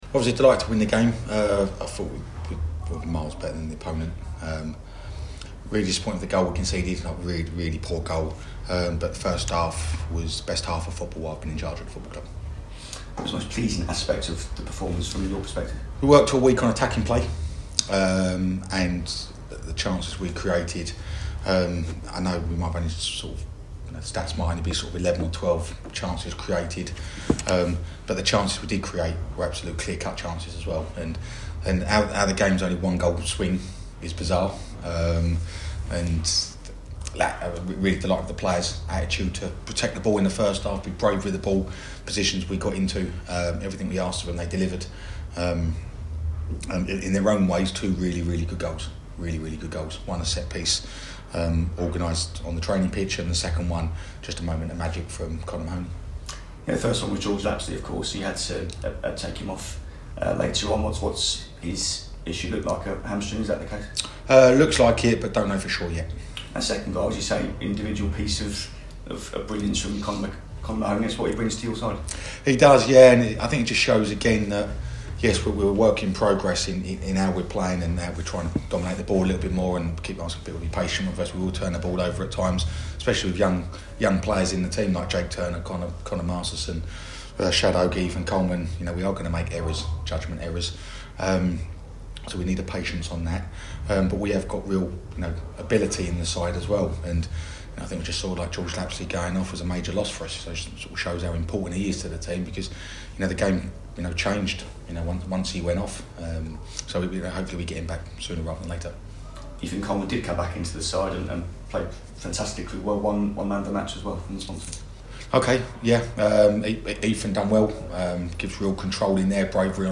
Listen: Gillingham manager Neil Harris reacts to their 2-1 win against Morecambe - which takes them back to the top of the League Two table